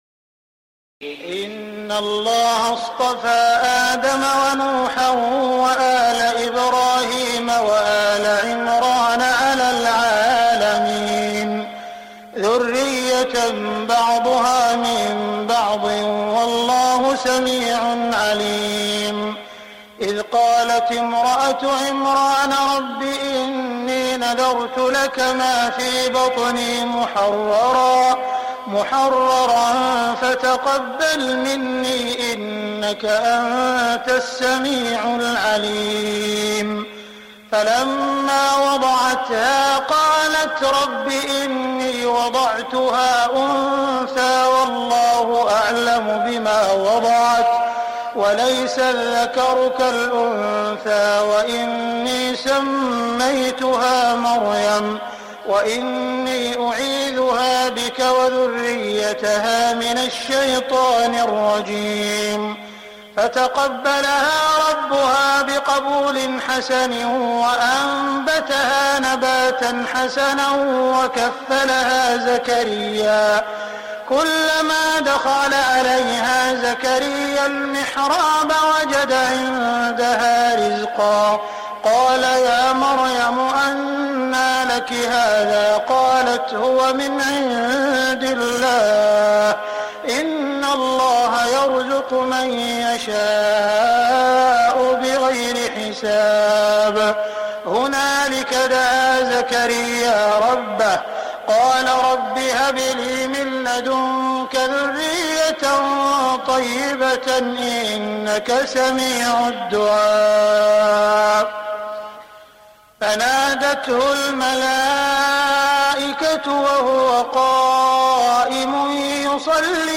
تهجد ليلة 23 رمضان 1418هـ من سورة آل عمران (33-92) Tahajjud 23 st night Ramadan 1418H from Surah Aal-i-Imraan > تراويح الحرم المكي عام 1418 🕋 > التراويح - تلاوات الحرمين